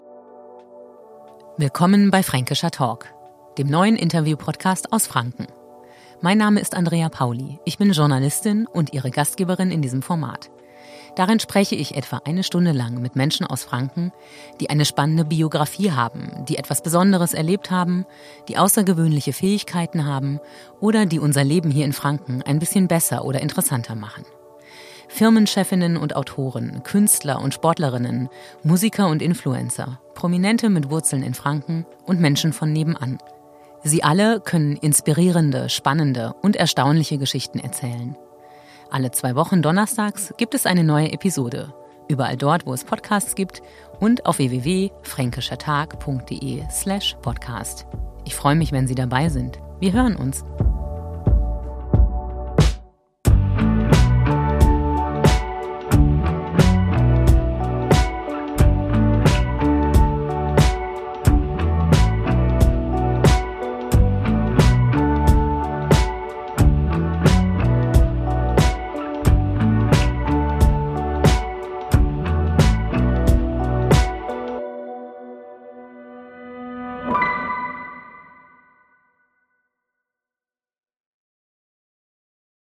Trailer: Fränkischer Talk